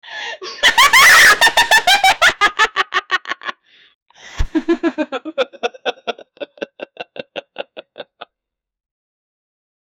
laugh--ho7jwyga.wav